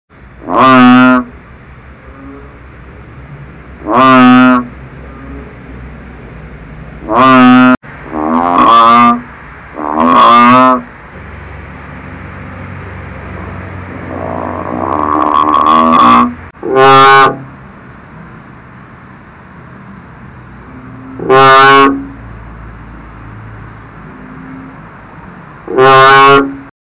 Yes, those things that honk in the night after a nice cool rain.
These guys are not as big as the sound they make.
But on listening back to the (amateur) recordings I made, that’s not the case.
Here’s a recording of three different bullfrogs. It’s my first time editing sounds together. Sorry if it blows your speakers.
3_bullfrogs.mp3